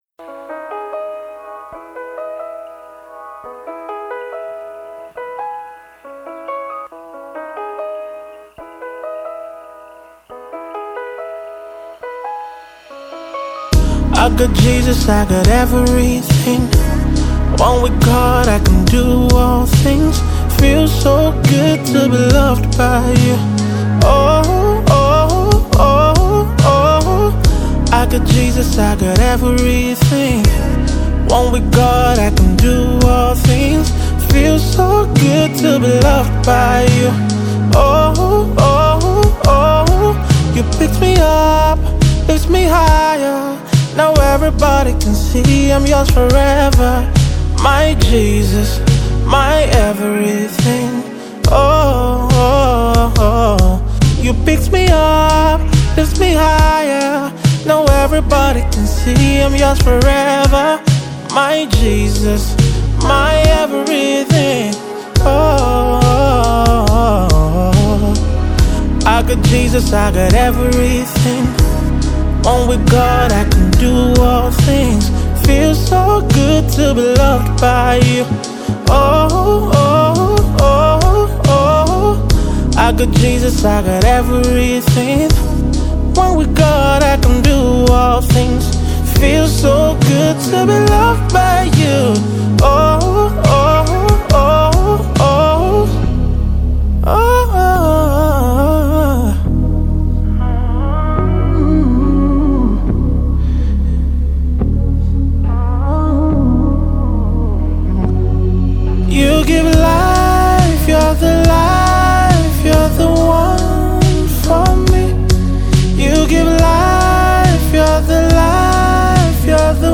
Nigeria Gospel Music
powerful worship song